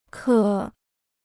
克 (kè): to be able to; to subdue.